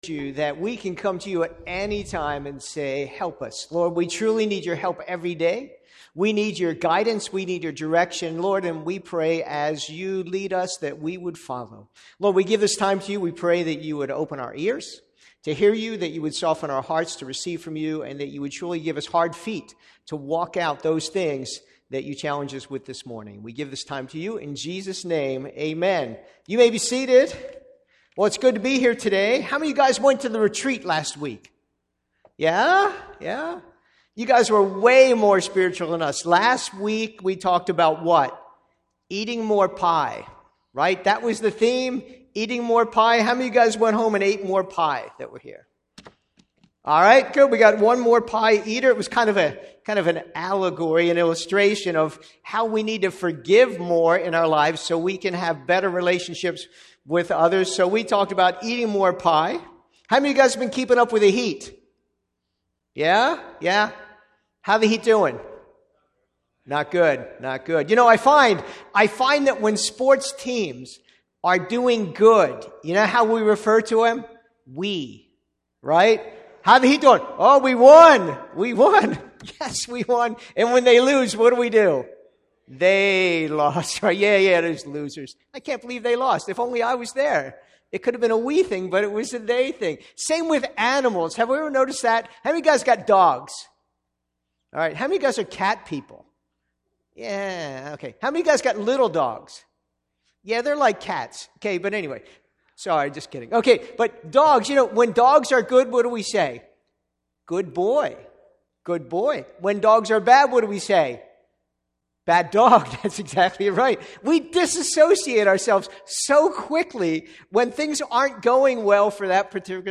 Past Sermons - Chinese Baptist Church of Miami